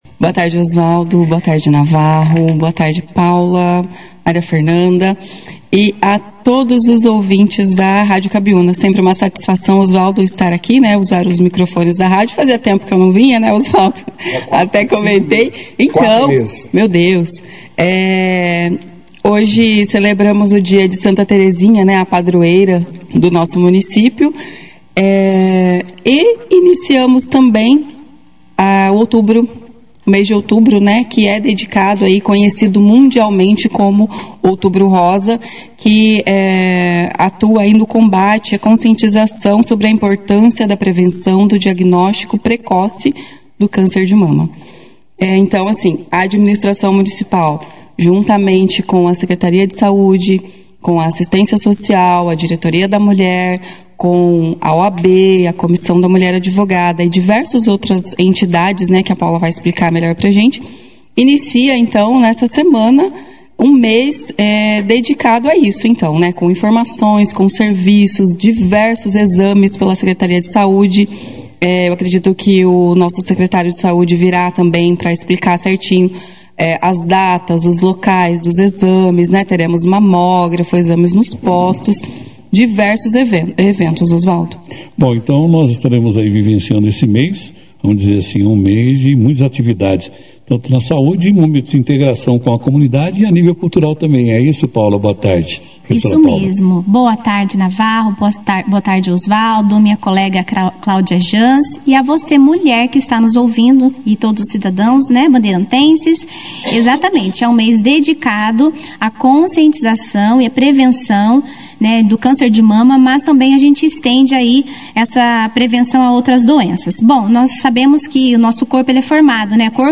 Na 2ª edição do jornal Operação Cidade, desta quarta-feira (1º), o destaque foi para o Outubro Rosa 2025, campanha mundial de conscientização sobre a prevenção e o diagnóstico precoce do câncer de mama.